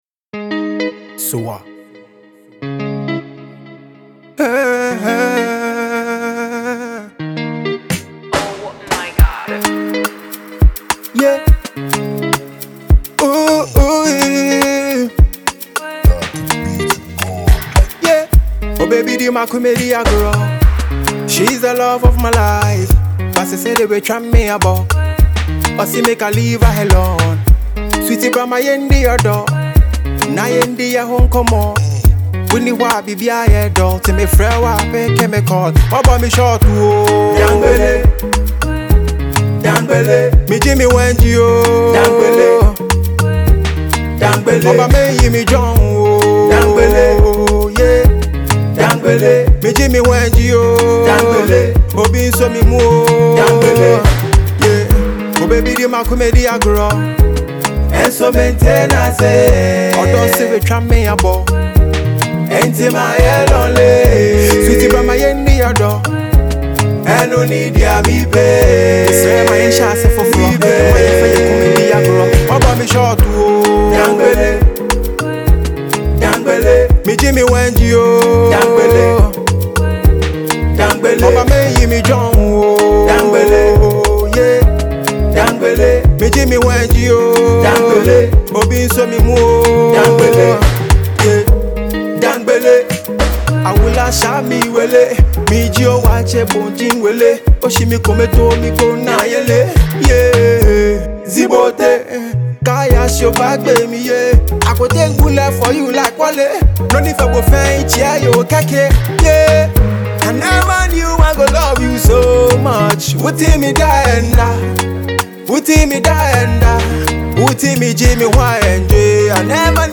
Fast-rising Ghanaian Afrobeats and Highlife singer
is a mid-tempo Afrobeats jam with Highlife singing vibe